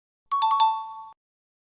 correct.mp3